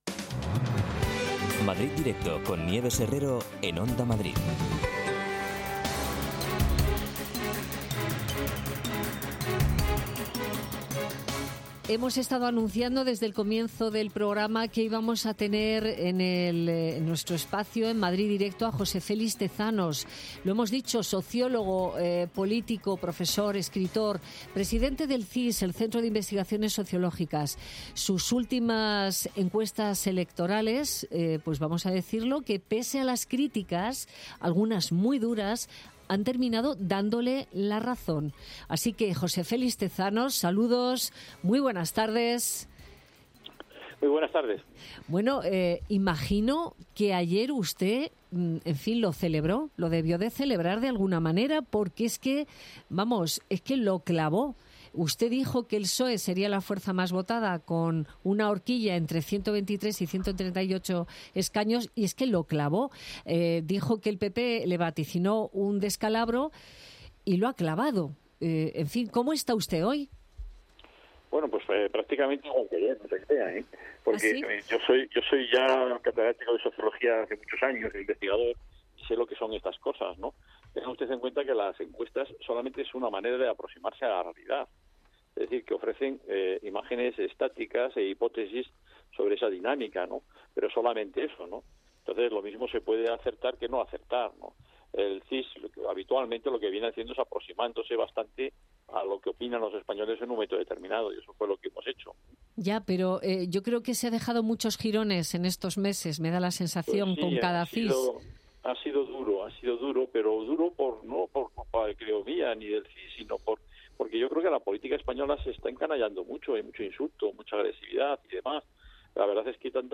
José Félix Tezanos, presidente del CIS, valora el resultado electoral